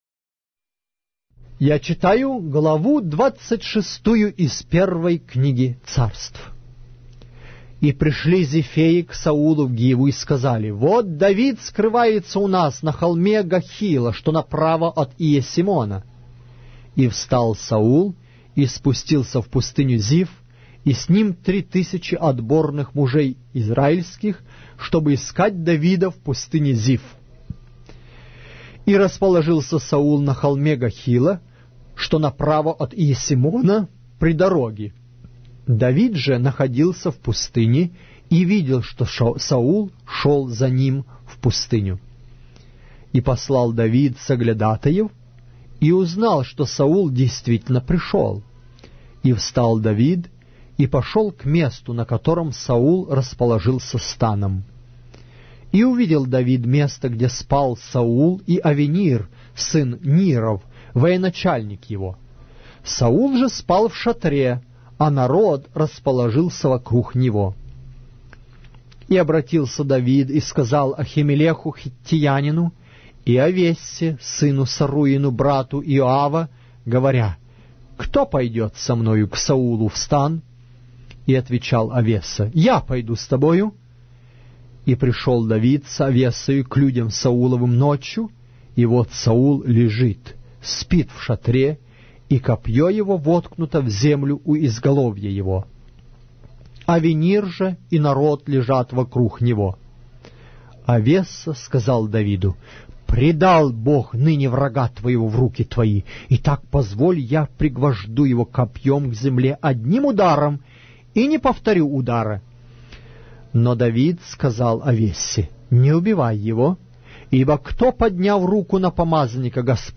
Глава русской Библии с аудио повествования - 1 Samuel, chapter 26 of the Holy Bible in Russian language